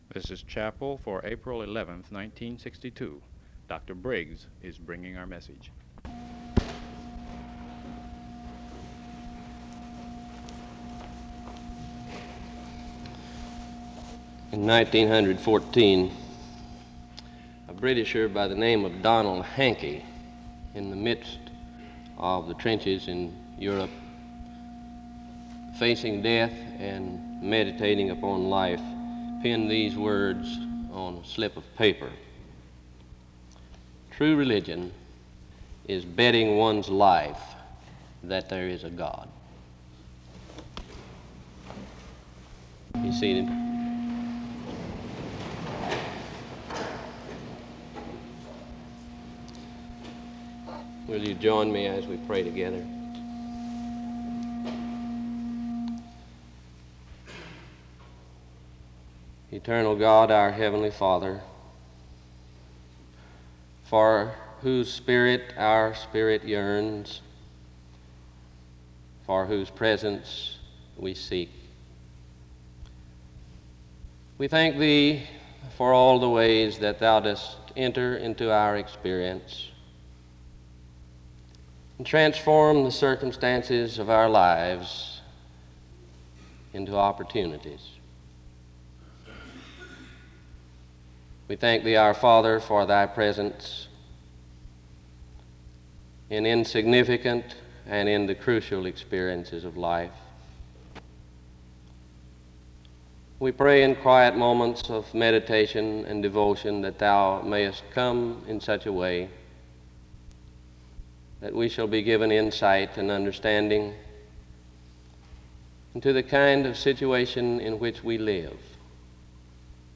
Download .mp3 Description The service begins with an opening word and prayer (00:00-04:11).
SEBTS Chapel and Special Event Recordings SEBTS Chapel and Special Event Recordings